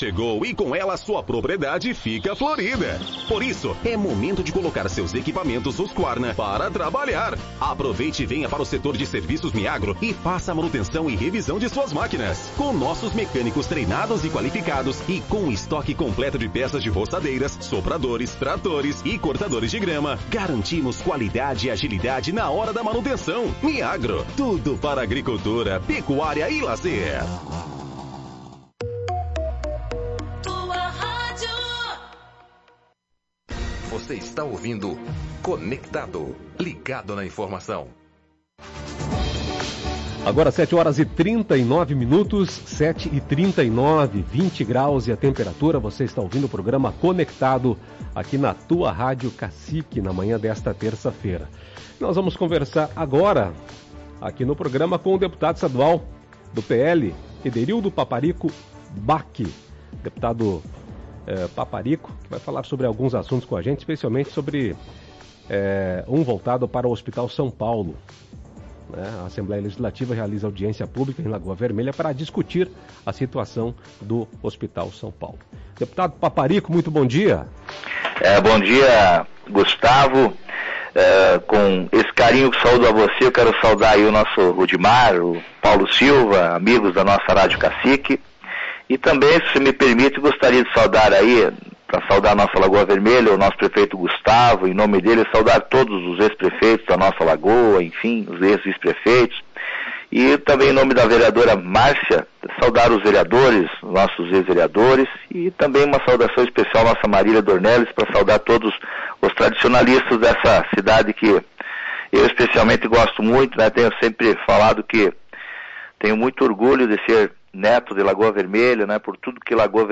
Ouça a participação do deputado Paparico Bacchi, do PL, no Conectado desta terça-feira, dia 29 de outubro.